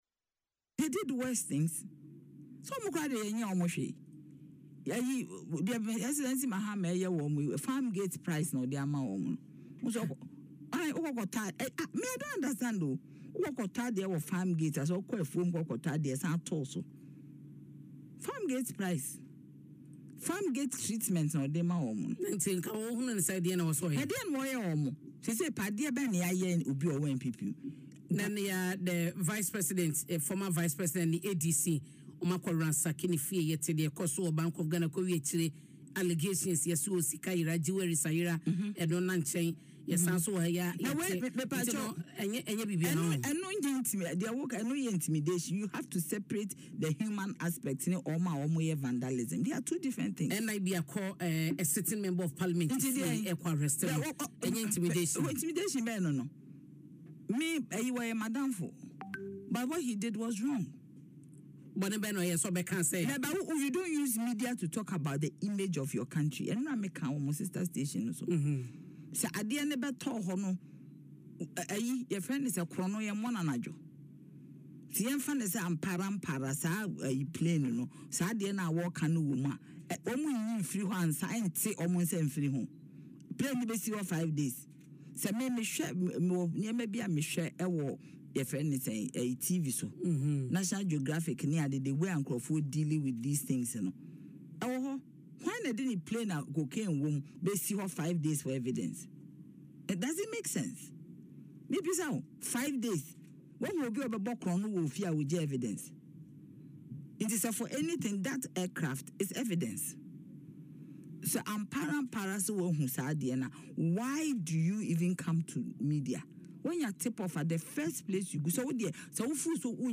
However, in an interview on Adom FM’s Dwaso Nsem, Jantuah rejected these claims, stating that the NPP members were being treated far better than they deserved.